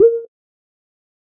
Warm-Tone-02.wav